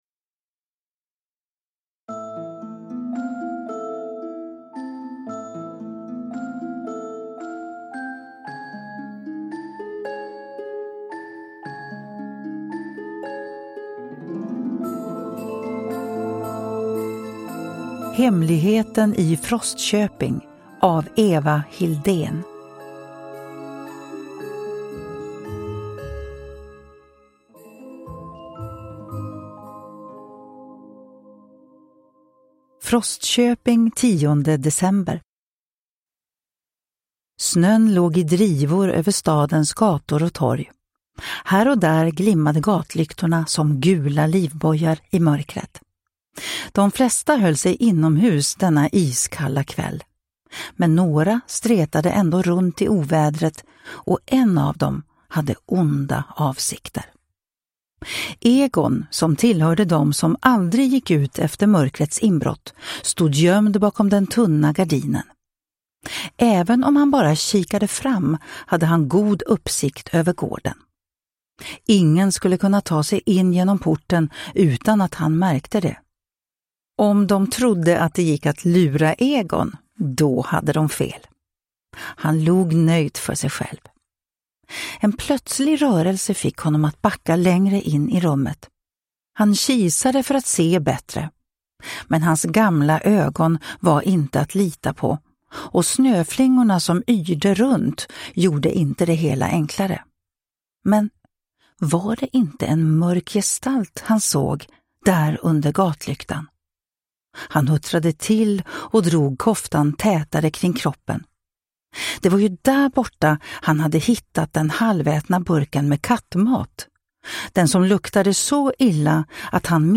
Hemligheten i Frostköping – Ljudbok